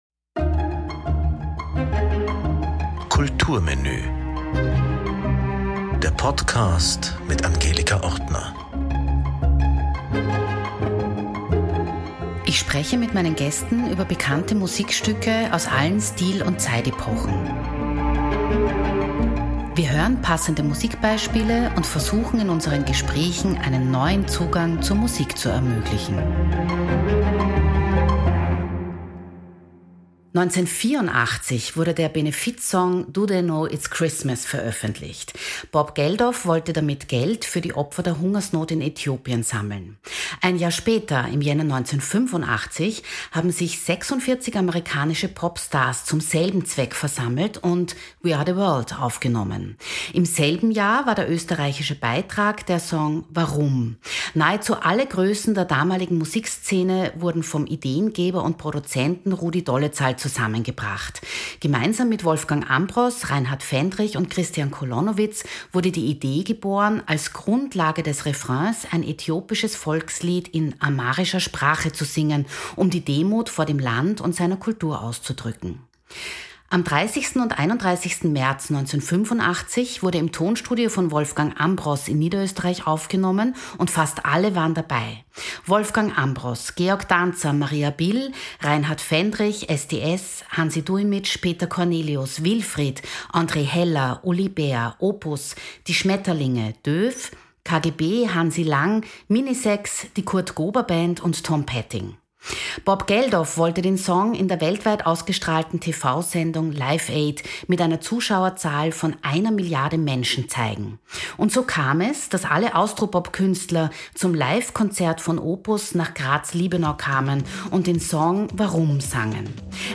Im Gespräch mit Produzent und Regisseur Rudi Dolezal 27.